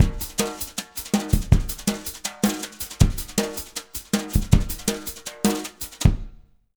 Index of /90_sSampleCDs/USB Soundscan vol.08 - Jazz Latin Drumloops [AKAI] 1CD/Partition A/06-160JUNGLB
160JUNGLE7-L.wav